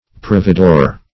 Search Result for " providore" : The Collaborative International Dictionary of English v.0.48: Providore \Prov"i*dore\, n. [See Provedore .]
providore.mp3